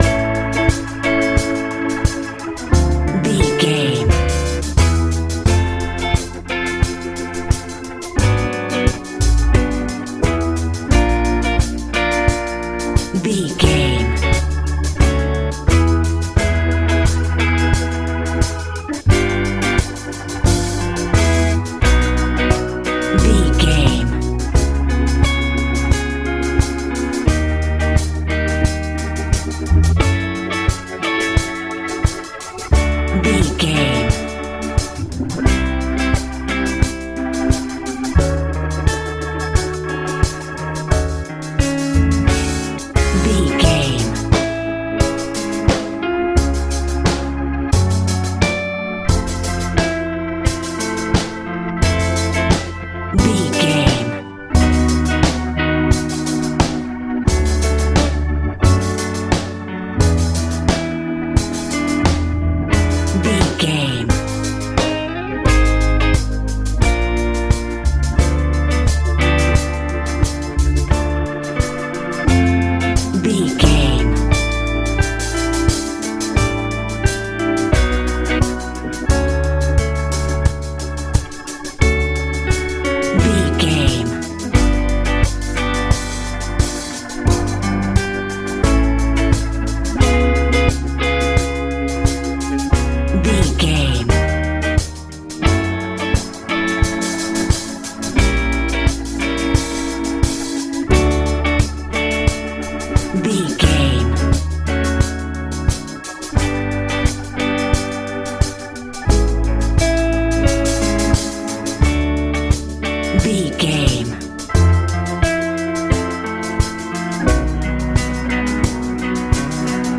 soulful pop feel
Ionian/Major
D
cool
confident
piano
organ
bass guitar
drums
positive
joyful